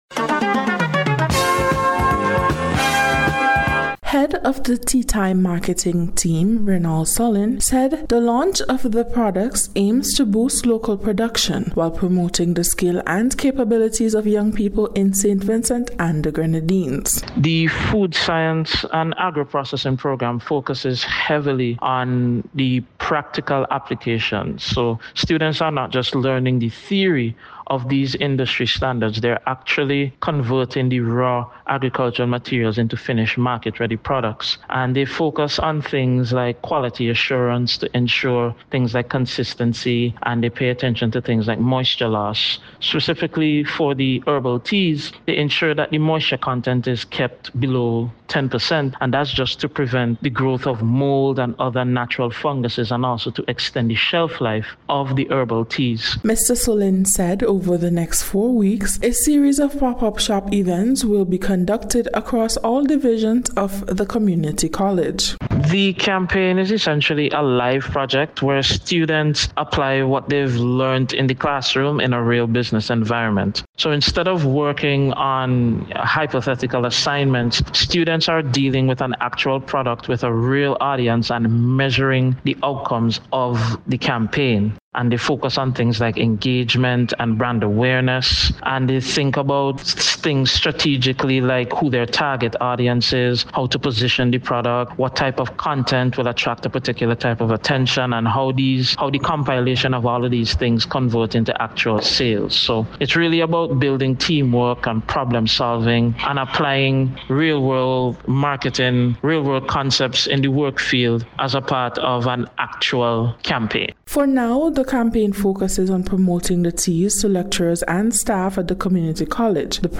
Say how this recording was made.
The launch was held on March 10th, at the Food Science Laboratory at the Division of Technical and Vocational Education of the College at Arnos Vale.